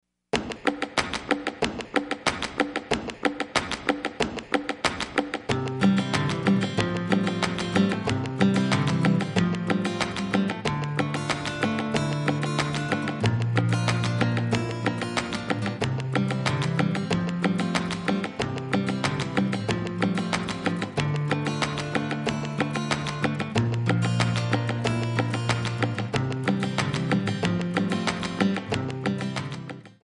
C#
MPEG 1 Layer 3 (Stereo)
Backing track Karaoke
Pop, 1990s